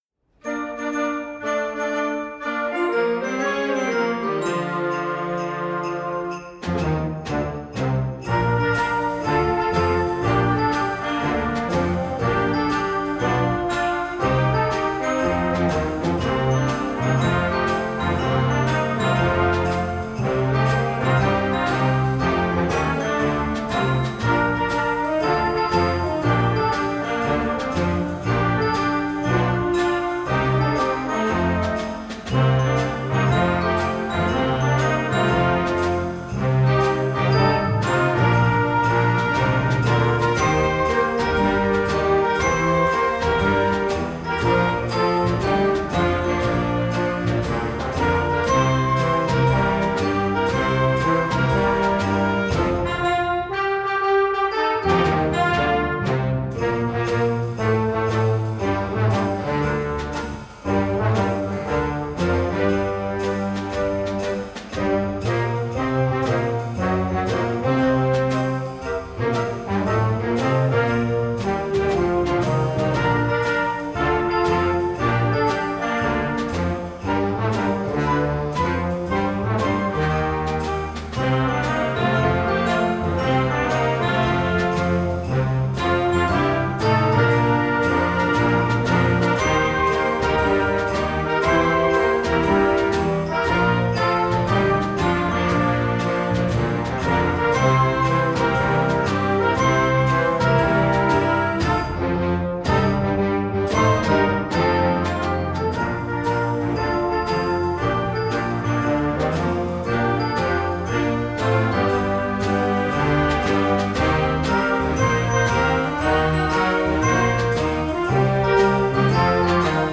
Gattung: Weihnachtslied für Jugendblasorchester
Besetzung: Blasorchester